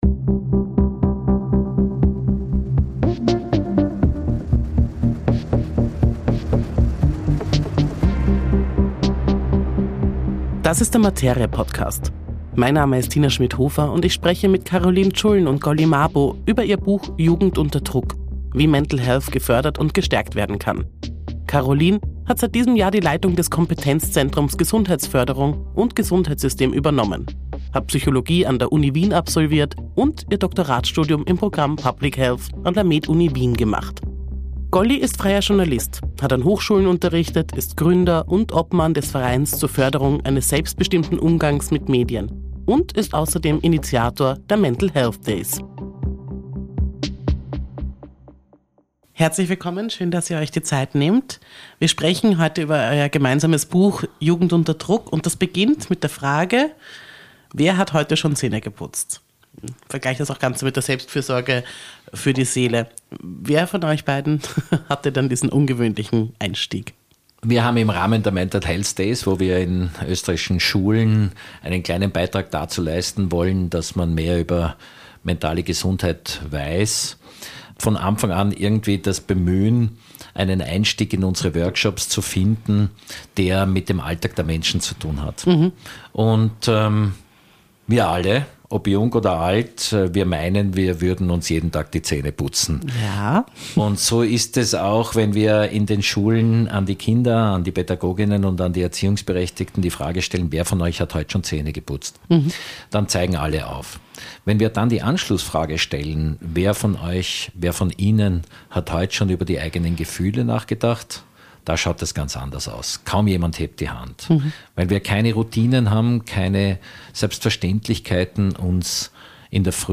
Ein Gespräch über den Mut, hinzuschauen, über Eltern, die keine Superheld:innen sein müssen, über Schulen, die mehr sein sollten als Notenfabriken, über Kinder, die lernen dürfen, anders zu sein – und Erwachsene, die endlich lernen müssen, das auszuhalten.